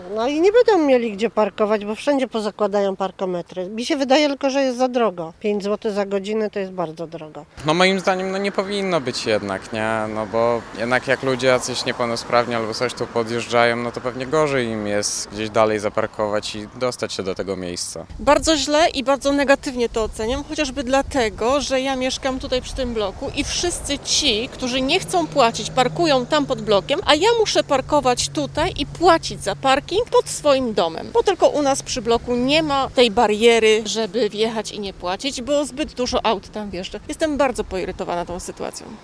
– Pacjenci szpitala parkują na naszych osiedlach, gdzie parking jest za darmo – skarżą się natomiast mieszkańcy: